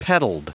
Prononciation du mot petalled en anglais (fichier audio)
Prononciation du mot : petalled